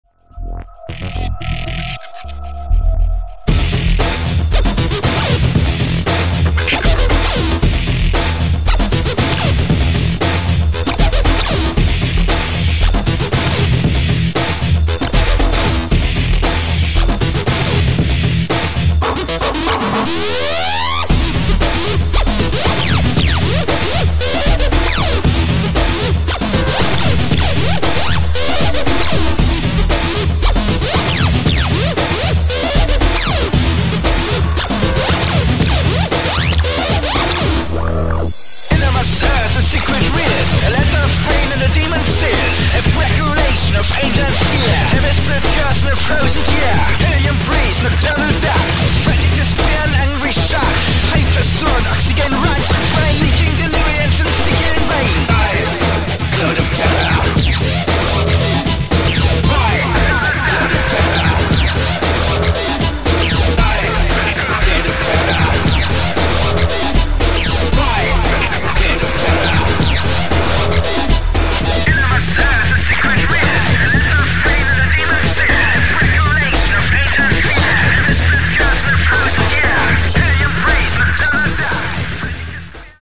"The Prodigy", a hard techno style with breakbeat
The tracks needed to feel aggressive